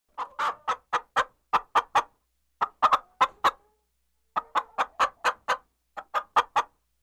clucking-chicken.mp3